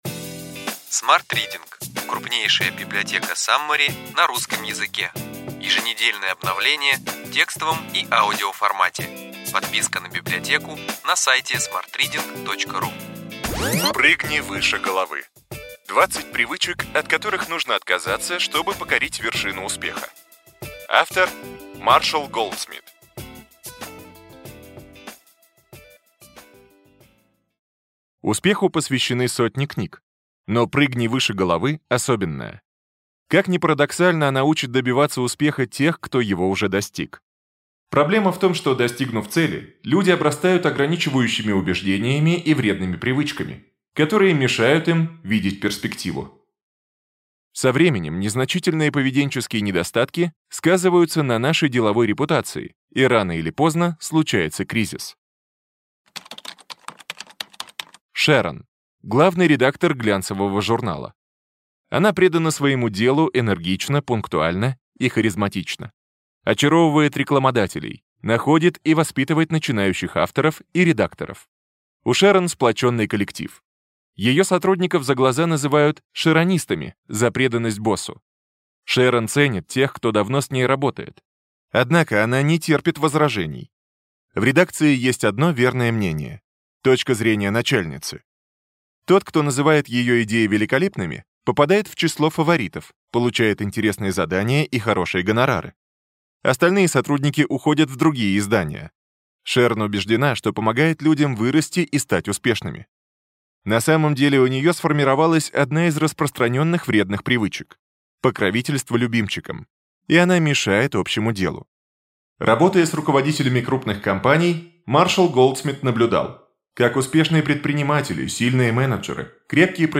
Аудиокнига Ключевые идеи книги: Прыгни выше головы! 20 привычек, от которых нужно отказаться, чтобы покорить вершину успеха.